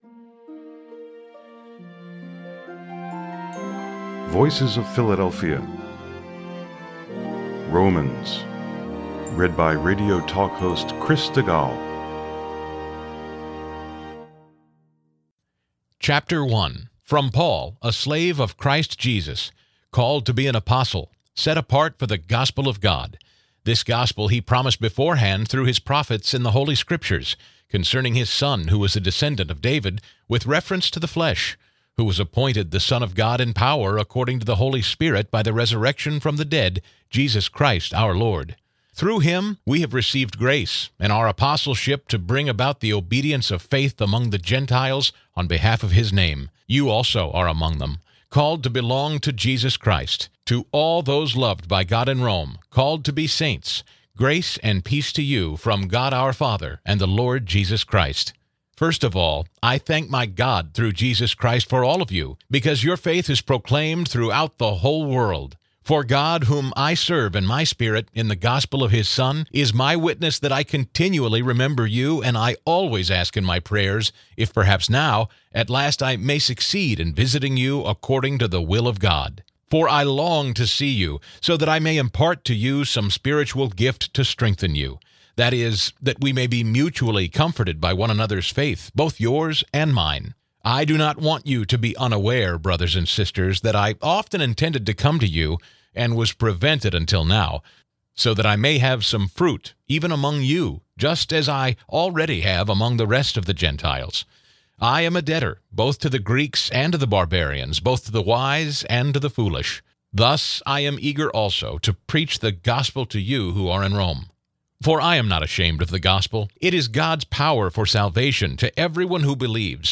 Reads the Apostle Paul’s Letter to the Romans (with Chapter 1 as a sampling)